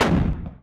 artillery.ogg